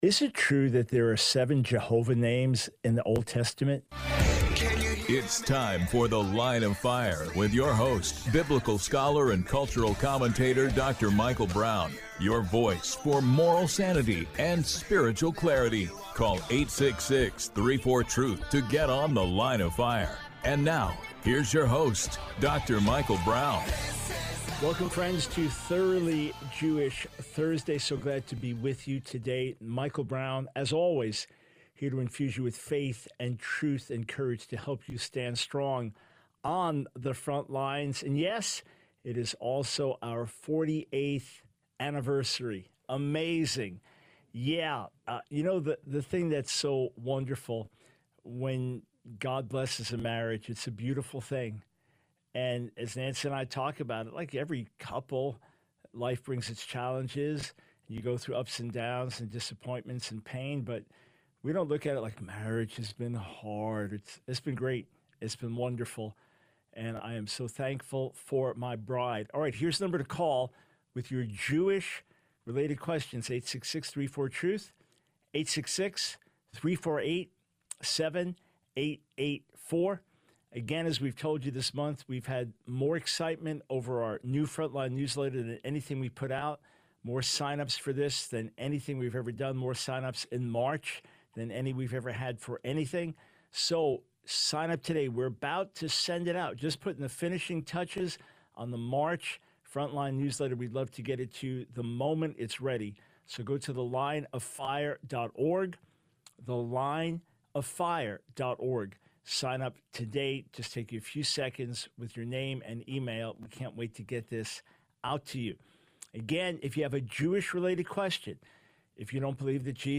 The Line of Fire Radio Broadcast for 06/13/24.